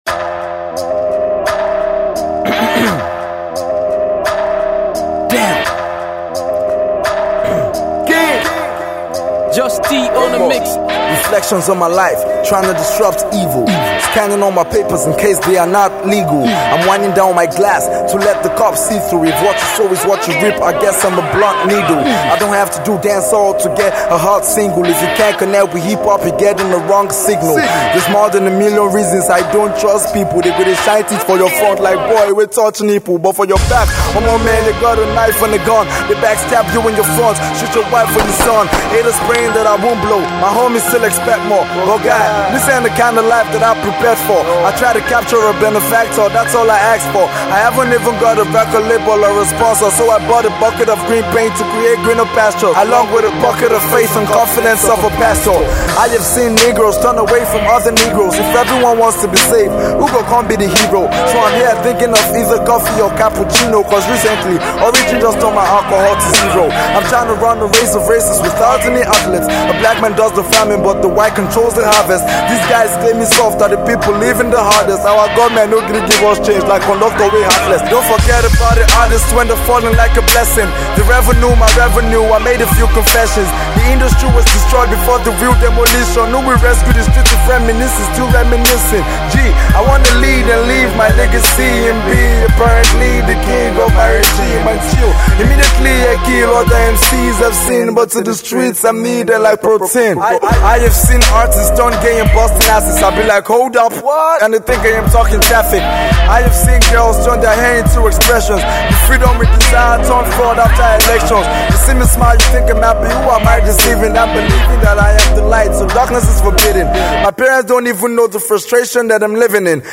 afro-pop dance anthem